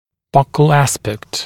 [ˈbʌkl ‘æspekt][ˈбакл ‘эспэкт]щечная сторона (зуба)